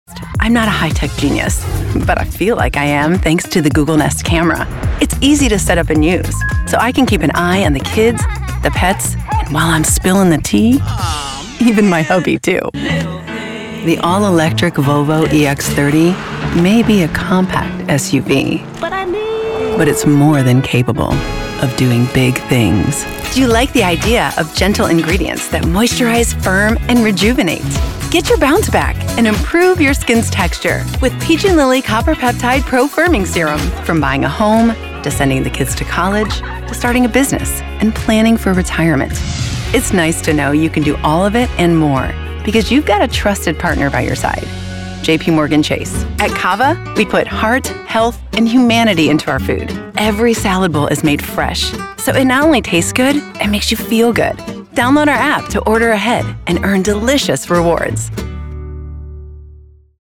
Meet our hand-selected roster of professional voice actors.